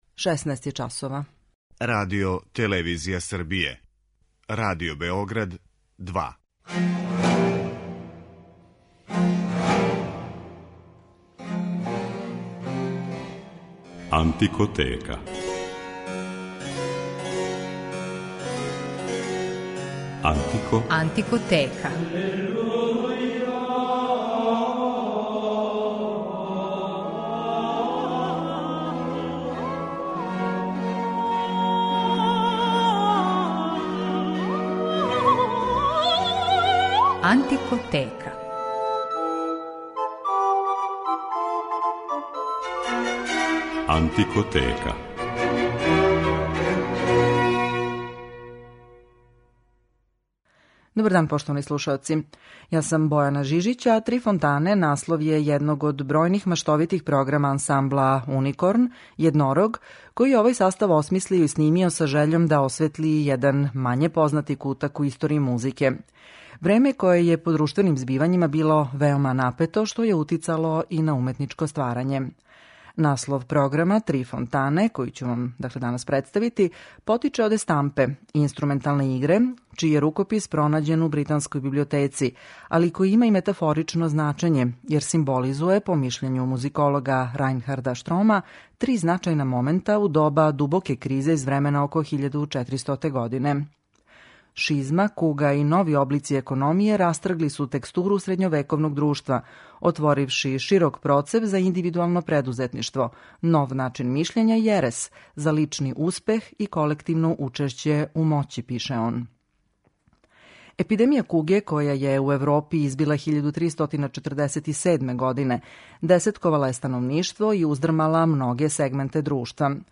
У данашњој емисији слушаћете маштовит програм са симболичним насловом 'Три фонтане', једног од најатрактивнијих састава посвећених извођењу ране музике на данашњој концертној сцени, аустријског ансамбла 'Уникорн' (Једнорог).
Овога пута, иза наслова "Три фонтане" крије се музика из турбулентног времена око 1400. године, са делима из тзв. стила Аrs Subtilior.